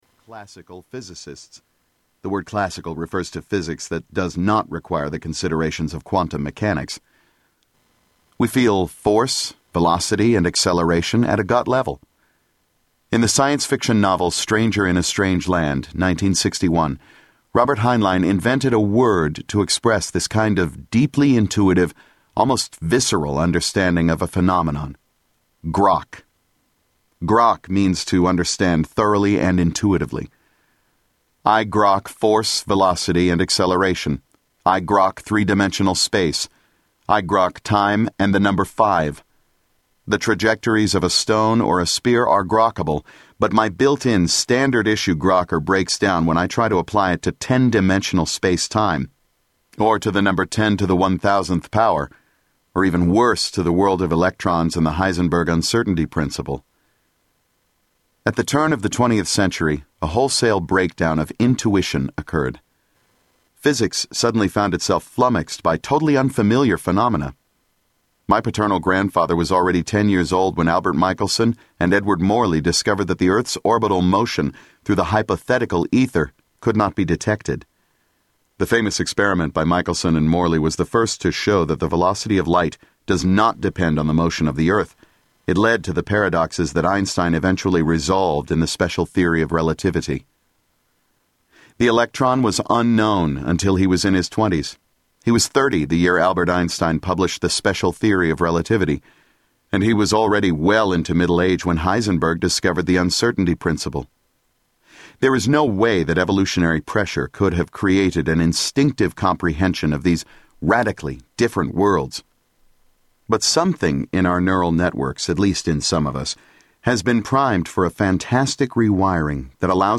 Tags: Quantum Physics Audio Books Quantum Physics Quantum Physics clips Physics Quantum Physics sound clips